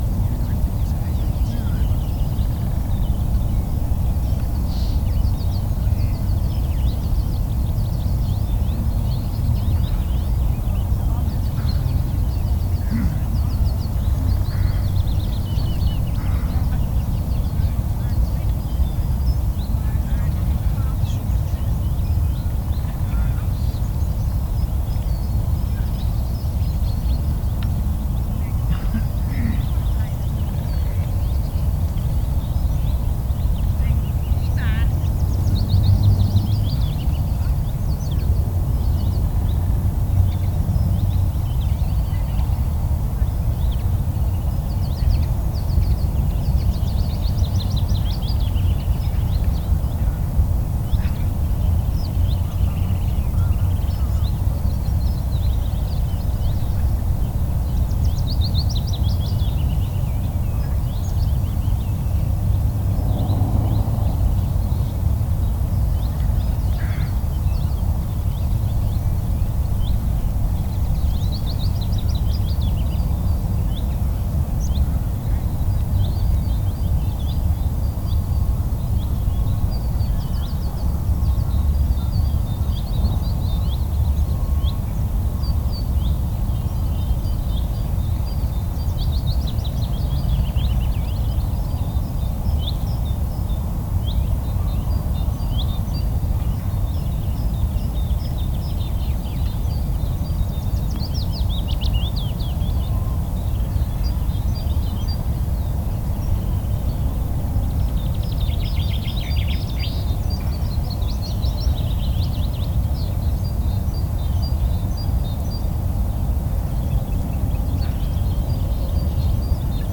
drifting sand area Loonse en Drunense Duinen Netherlands 1032 am 250404_1067
Category 🌿 Nature
ambiance ambience ambient atmospheric background-sound birds calm drifting-sand-dunes sound effect free sound royalty free Nature